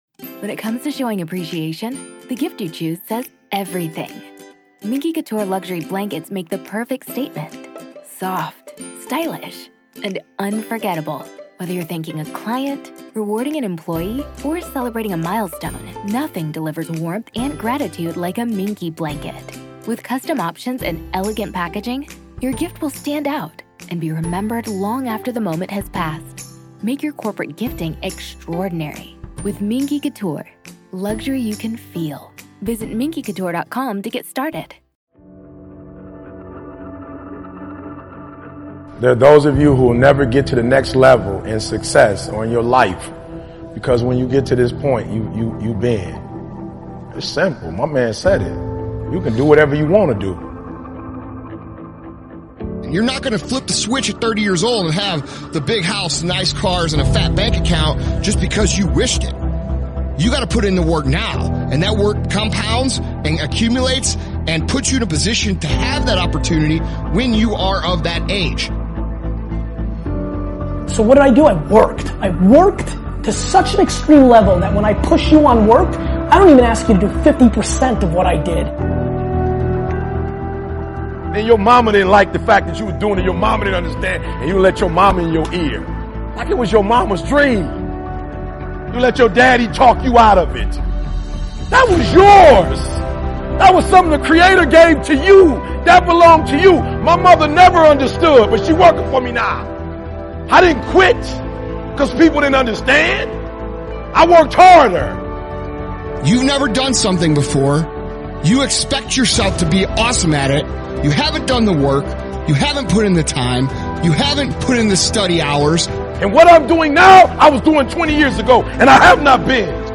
Speakers: Eric Thomas